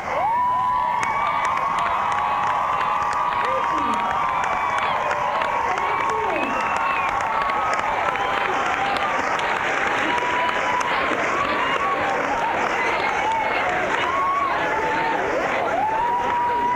06. crowd (0:16)